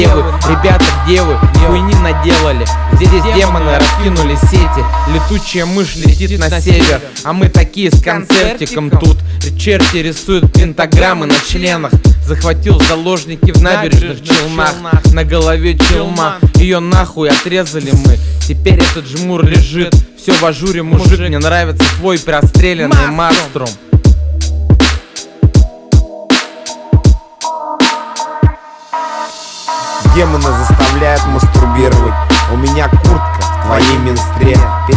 Underground Rap Hip-Hop Rap